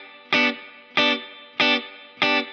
DD_TeleChop_95-Gmaj.wav